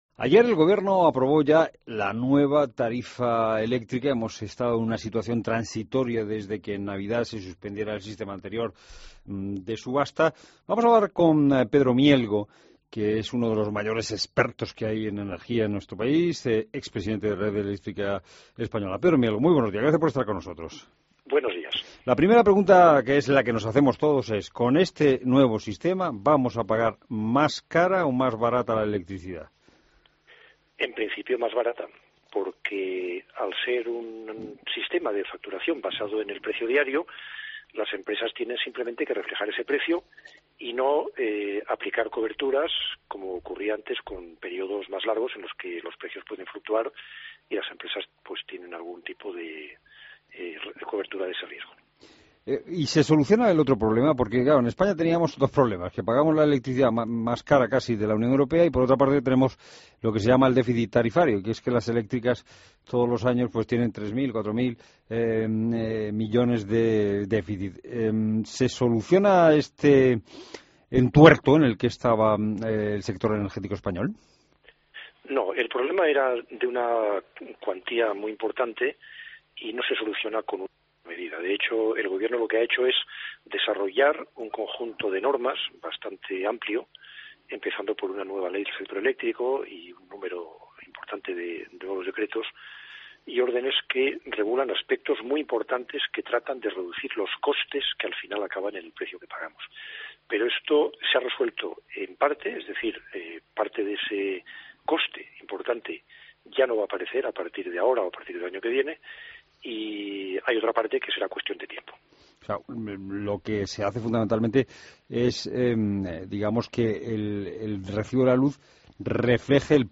La Mañana Fin de Semana Entrevista